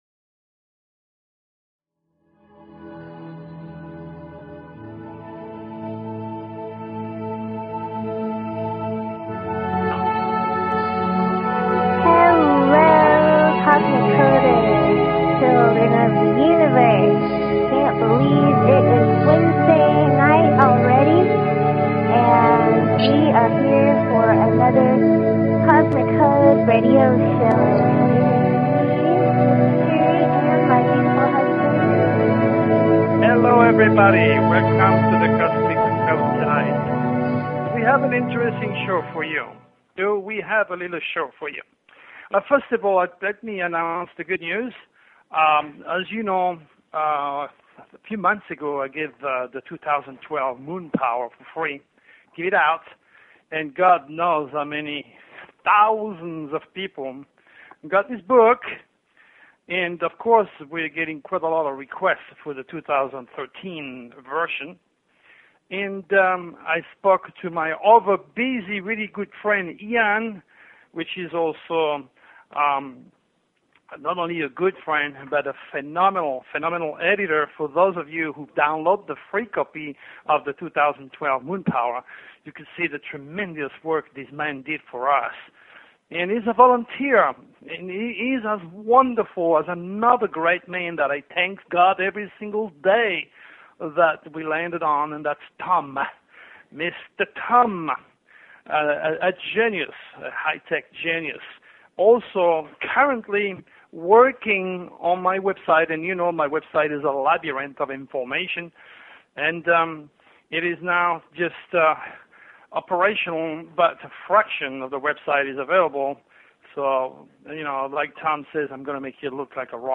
Talk Show Episode, Audio Podcast, The_Cosmic_Code and Courtesy of BBS Radio on , show guests , about , categorized as